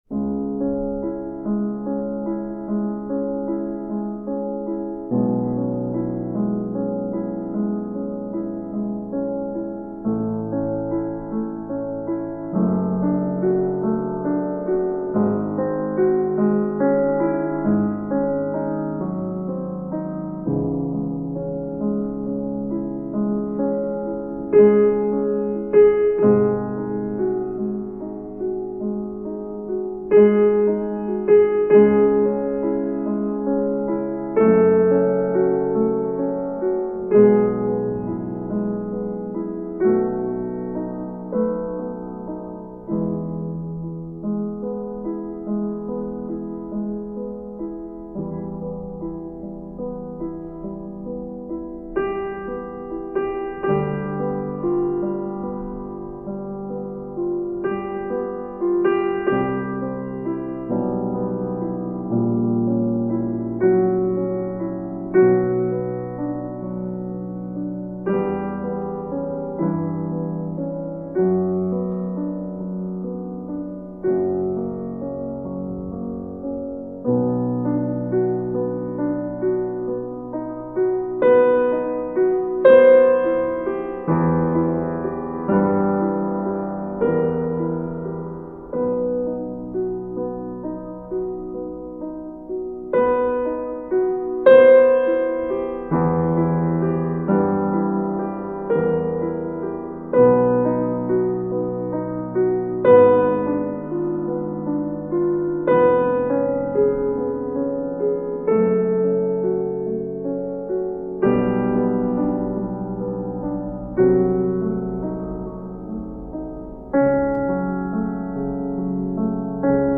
Когда Вас посещает бунтарство Вашего Сознания, смело включайте эту мелодию и она вам поможет обрести умиротворенность и спокойствие, а значит и поможет возвыситься над текущими проблемами, усмирит и придаст коррекцию состояниям аномального характера.